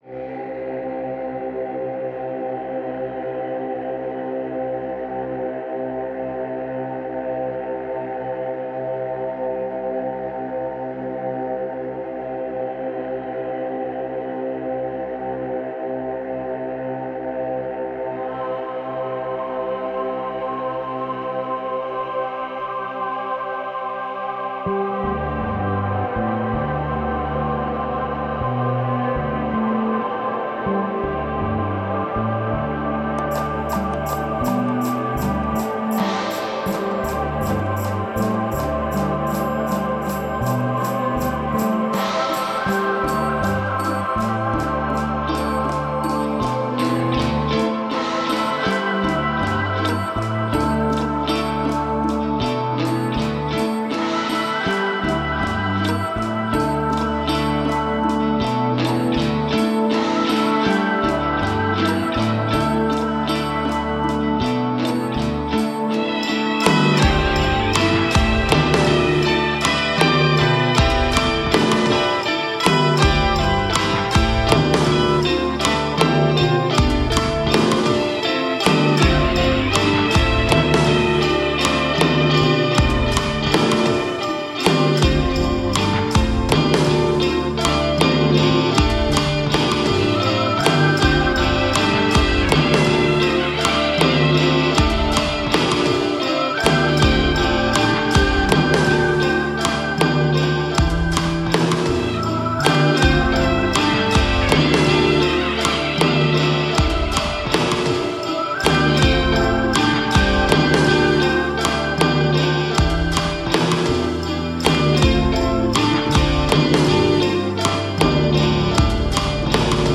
Ambient Trance Prog